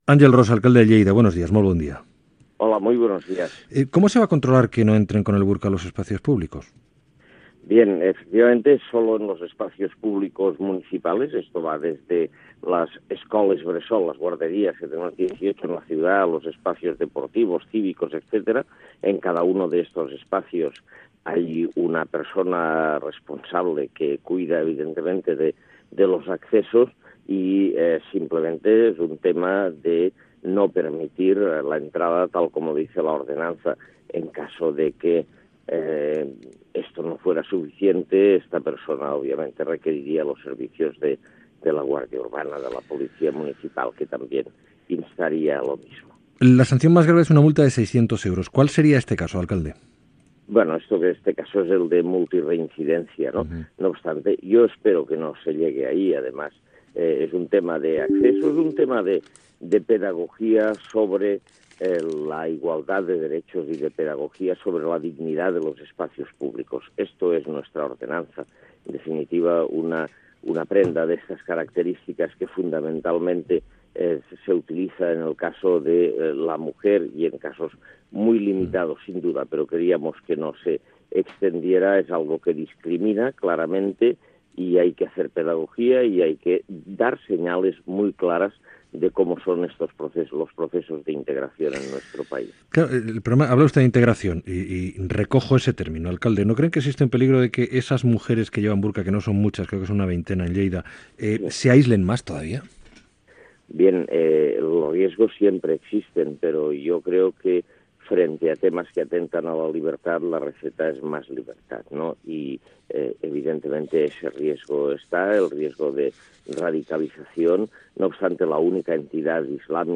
Fragment d'una entrevista a l'alcalde de Lleida Àngel Ros, sobre la utilització del burka.
Info-entreteniment